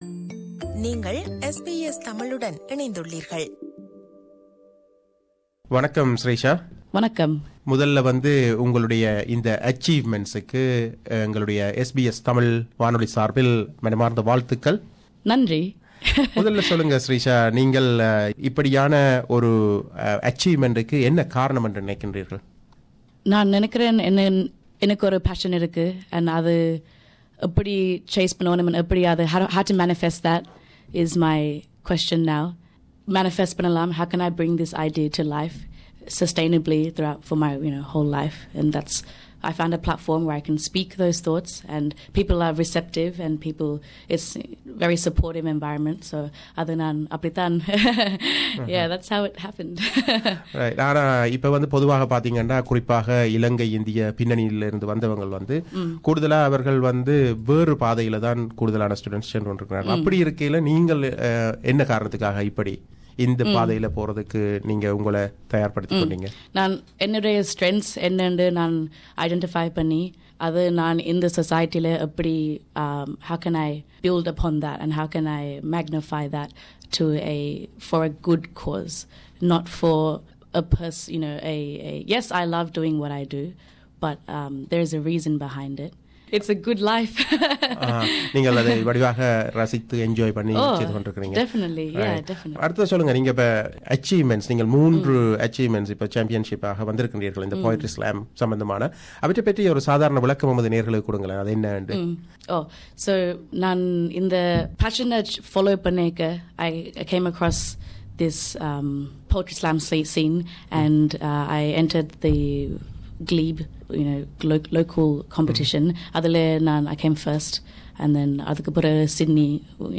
அவரைச் சந்தித்து உரையாடியுள்ளார்